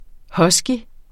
Udtale [ ˈhʌsgi ]